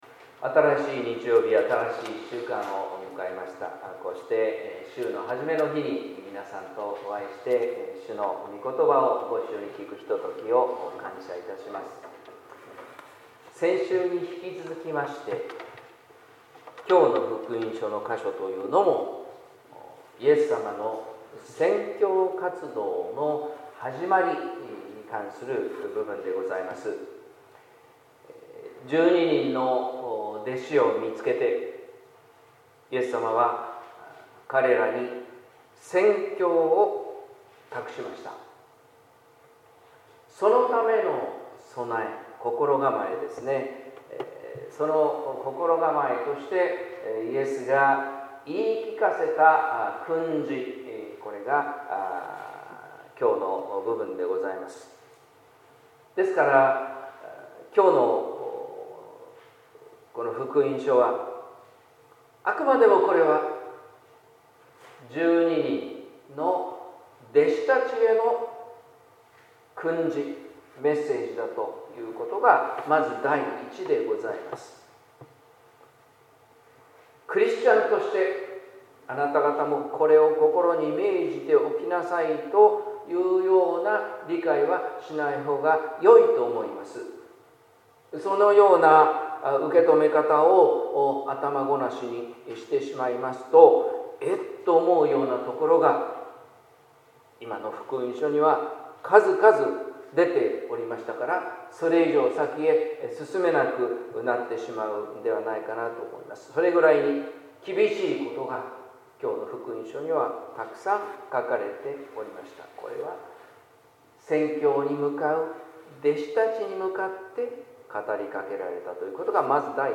説教「蛇のように、鳩のように」（音声版） | 日本福音ルーテル市ヶ谷教会